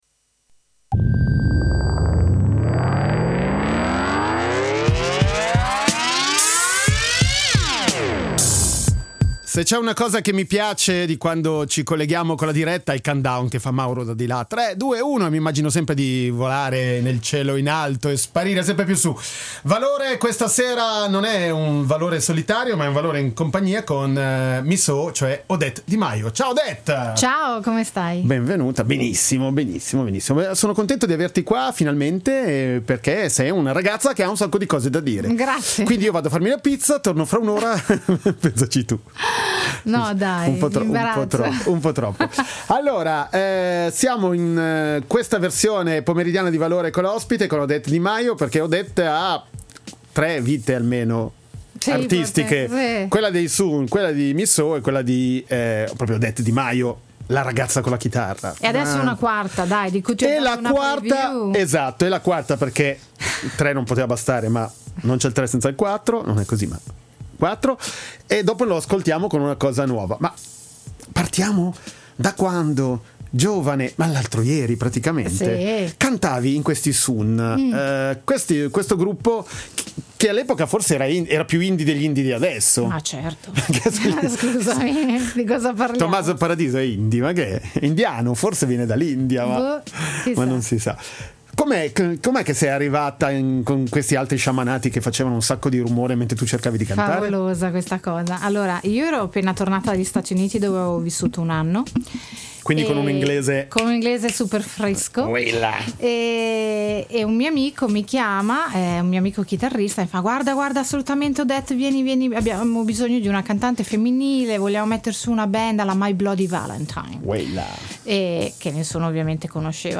Musica e parole al servizio di pensieri in libertà, si passa dal rock al cantautorato.